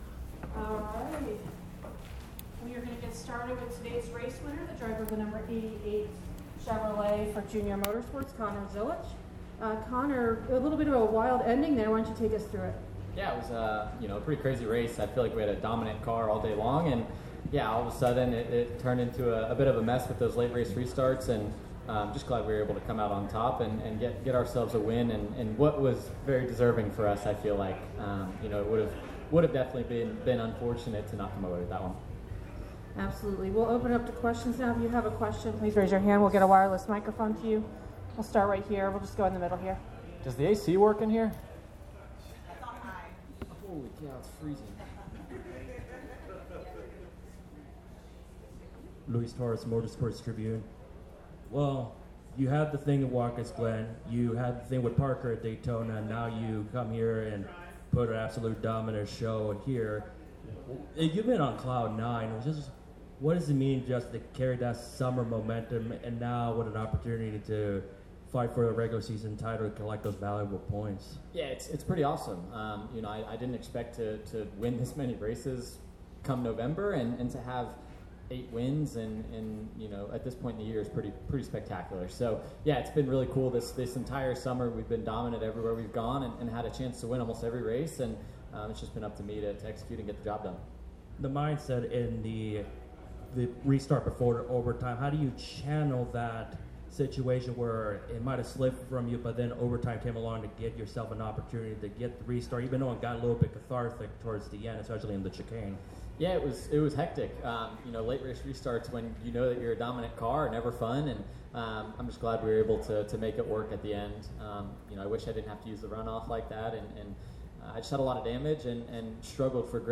Interview:
NASCAR Xfinity Series (Portland International Raceway) race winner Connor Zilisch (No. 88 JR Motorsports Chevrolet) –